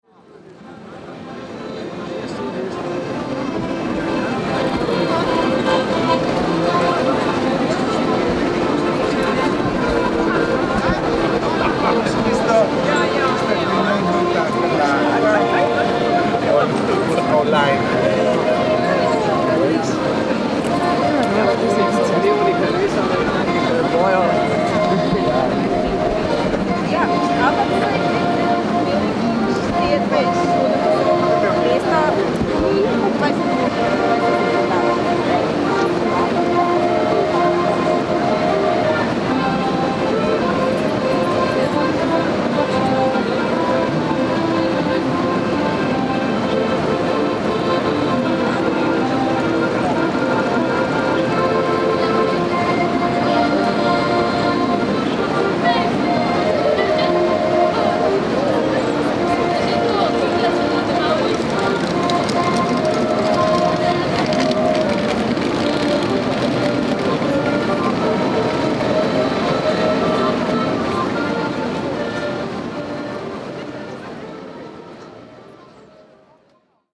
Sounds of Christmas market in Ljubljana city centre. On 20th of December 2018 our team recorded what does Christmas market in the city centre of Ljubljana sounds like. Soundscape of people talking, walking, some adverts and song played by a street performer on the accordion in the background.
• Soundscape
• sound walk